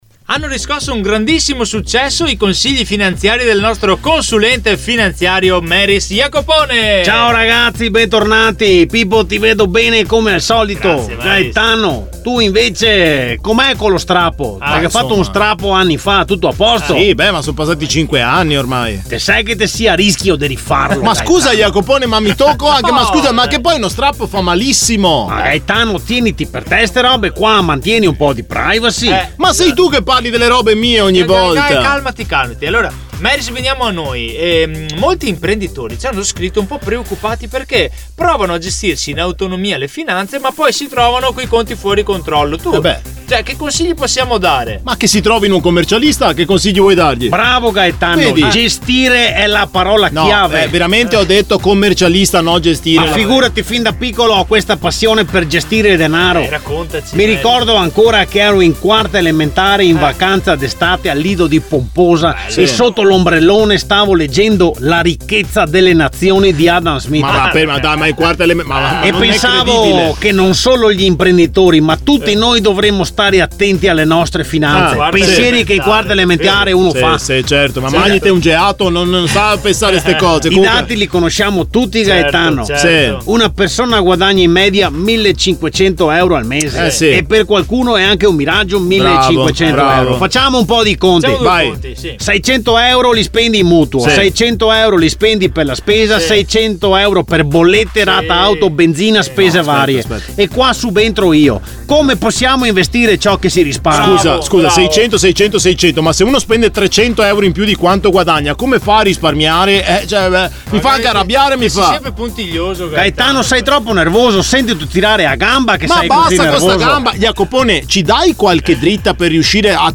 🎙 Ogni settimana, uno sketch ironico e fuori dagli schemi ha raccontato – a modo nostro – quanto può fare la differenza affidarsi a chi lavora con PROFIS.
Tra gag, colpi di scena e un consulente un po’... così, il risultato è tutto da ascoltare!